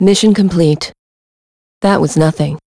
Ripine-Vox_Victory.wav